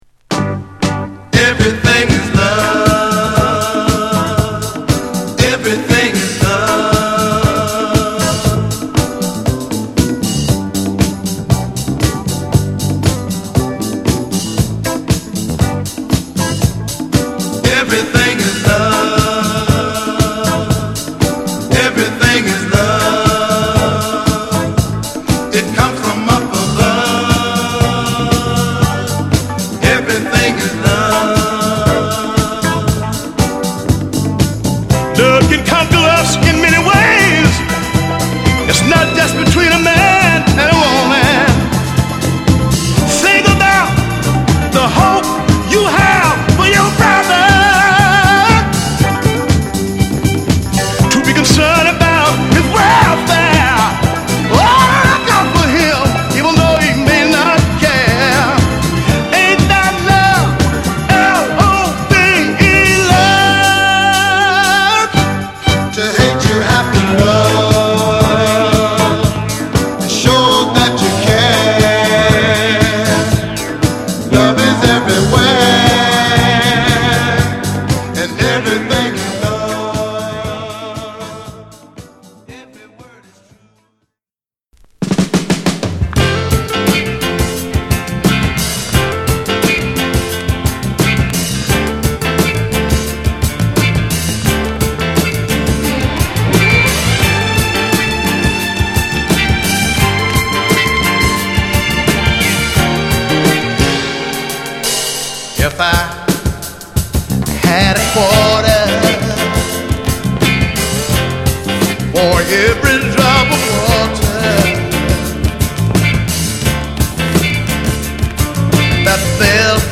上質なオケにゴスペルで鍛えた喉が炸裂する一枚。